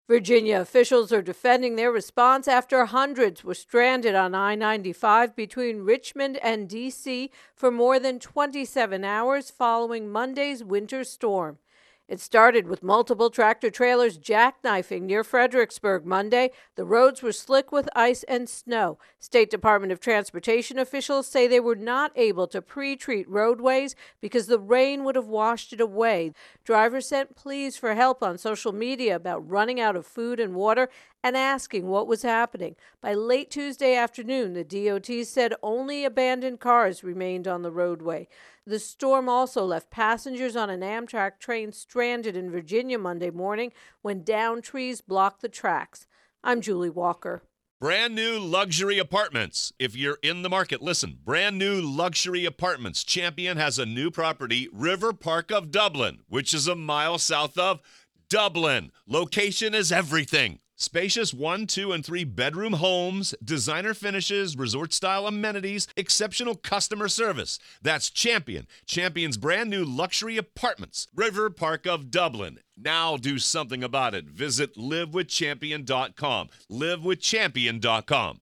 self contained intro + voicer for Winter Weather Interstate Shutdown